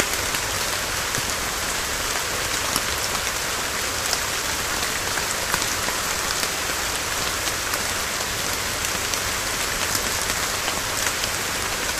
Rain2.ogg